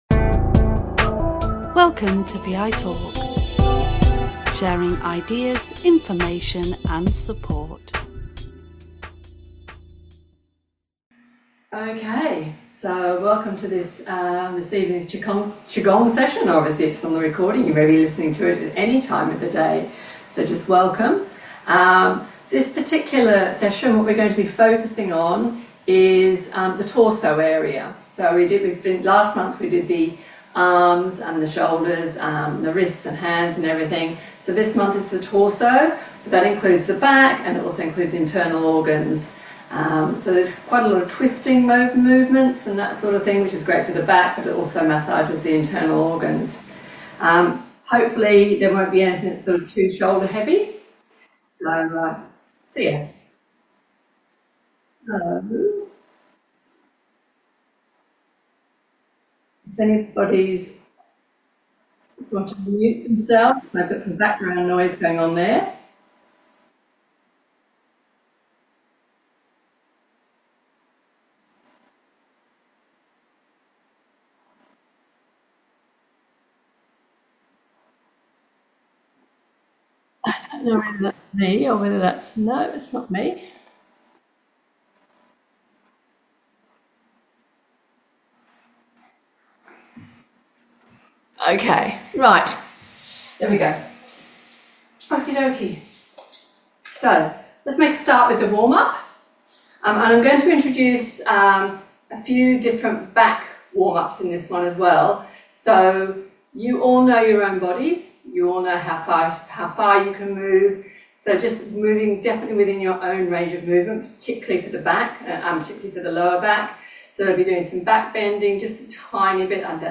QiGong is gentle, mindful movement which can be done either seated or standing. Sessions take place on zoom on the first Monday of the month at 8 pm.